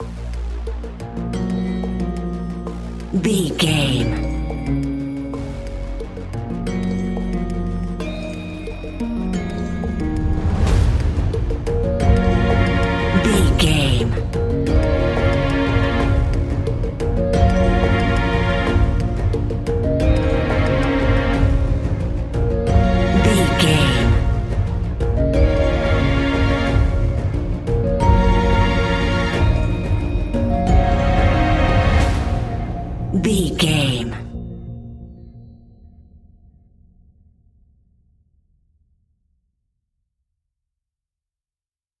Scary Background Industrial Music 30 Sec.
Aeolian/Minor
A♭
ominous
eerie
synthesizer
strings
horror music